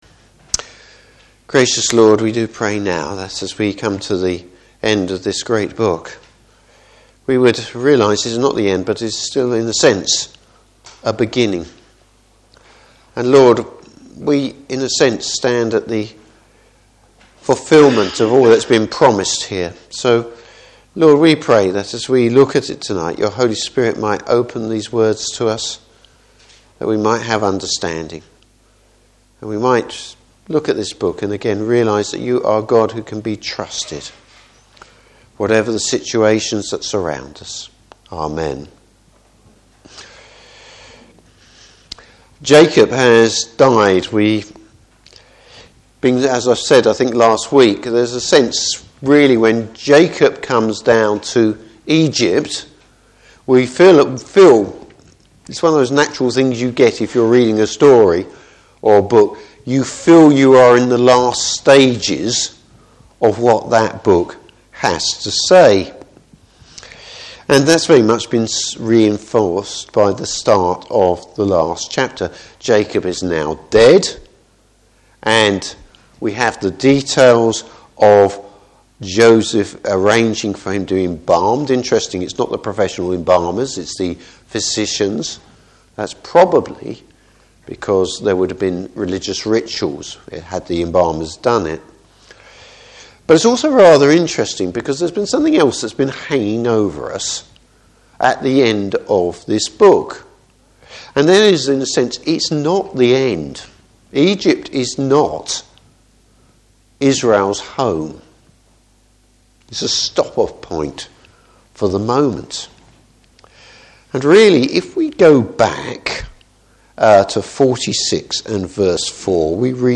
Passage: Genesis 50. Service Type: Evening Service The adventure isn’t over!